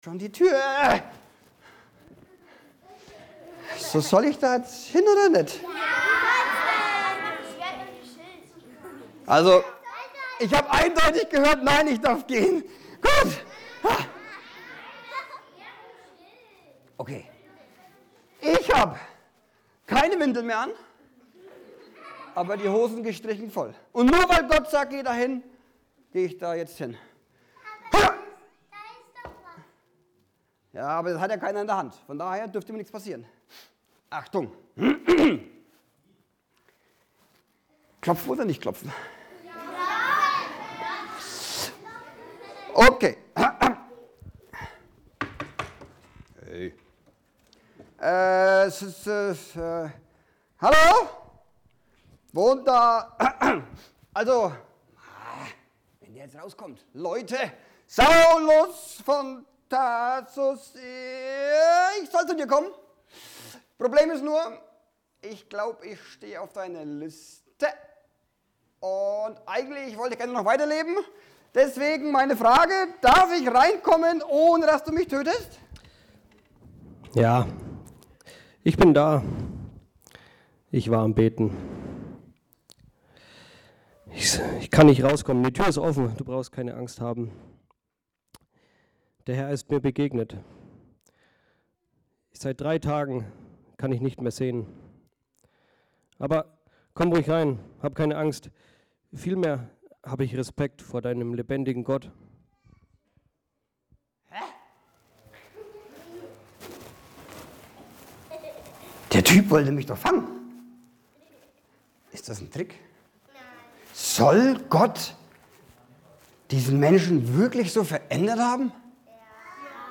Familiengottesdienst